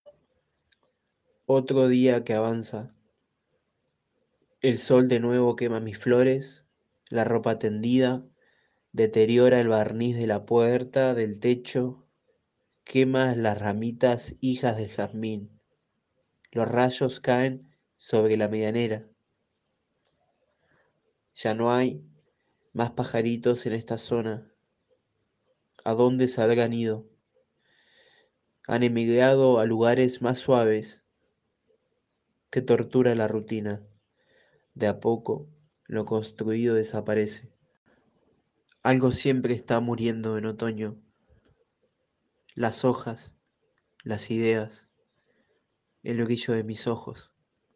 Para escuchar aigunos poemas en la voz del autor, click abajo en Link de descarga.